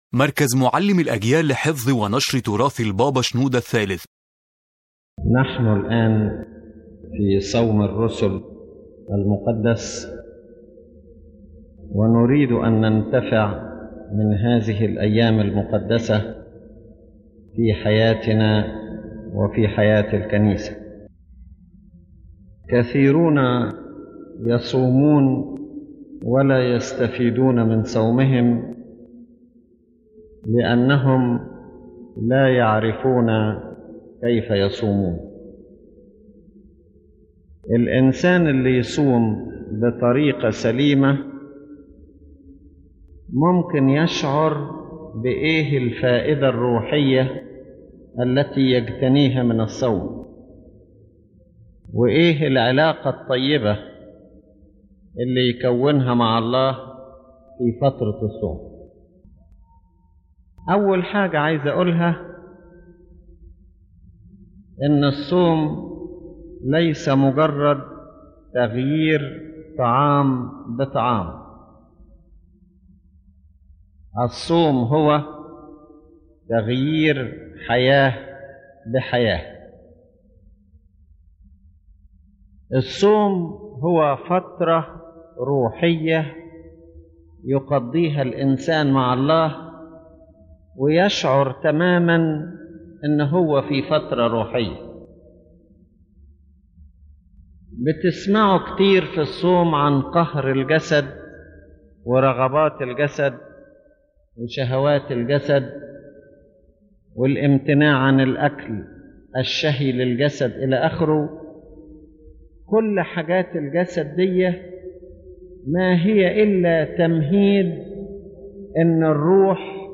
⬇ تحميل المحاضرة يتحدث قداسة البابا شنوده الثالث عن أن الصوم ليس مجرد امتناع عن الطعام، بل هو حياة روحية متكاملة تهدف إلى التقرب من الله من خلال التوبة والصلاة والعمل الروحي.